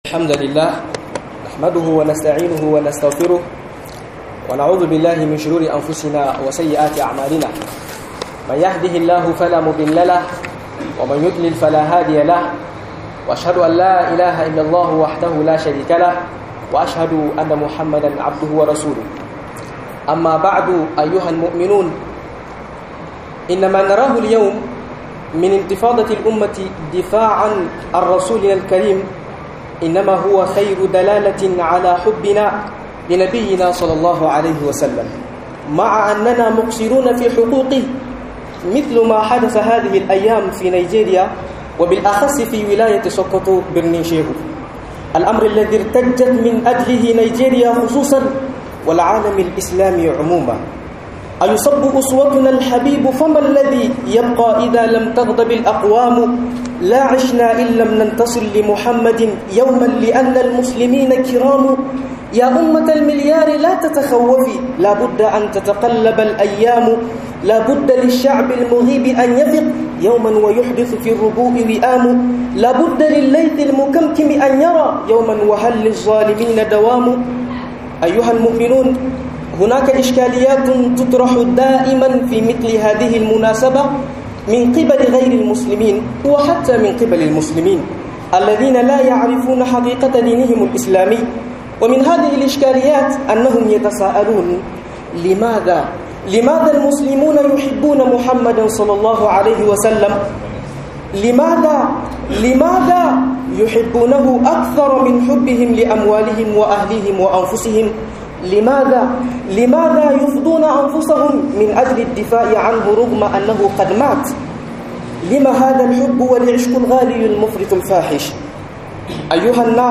Danmi yasa muke son Manzon Allah - MUHADARA